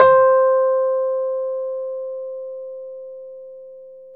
RHODES-C4.wav